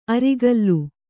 ♪ arigallu